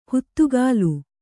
♪ huttugālu